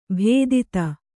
♪ bhēdita